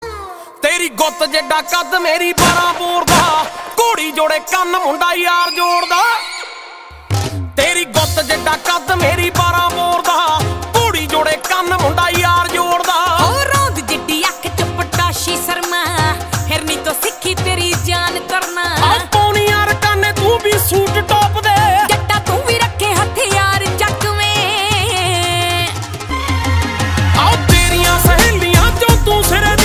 Punjabi song